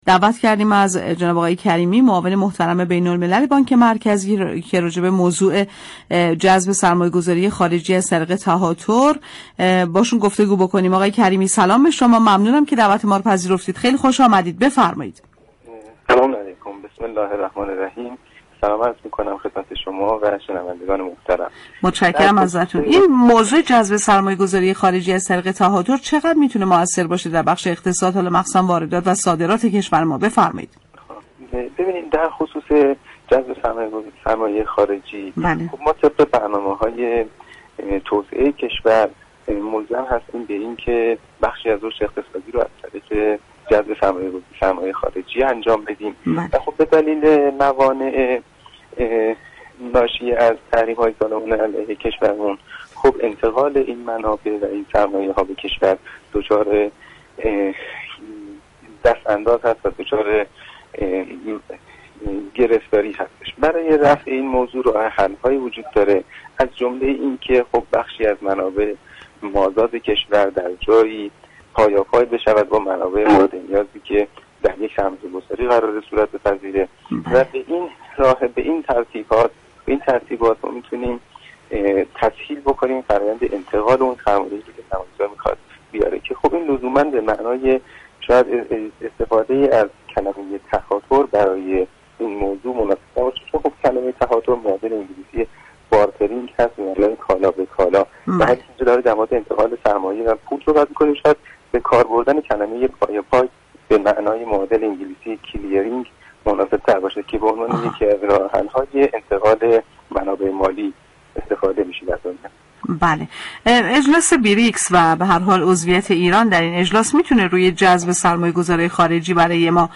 به گزارش پایگاه اطلاع رسانی رادیو تهران، محسن كریمی معاون بین‌الملل بانك مركزی در گفت و گو با «بازار تهران» درخصوص تاثیر جذب سرمایه گذار خارجی بر اقتصاد كشور از طریق تهاتر؛ اظهار داشت: طبق برنامه‌های توسعه‌ای كشور ملزم هستیم بخشی از رشد اقتصادی كشور را از طریق جذب سرمایه‌ خارجی تامین كنیم.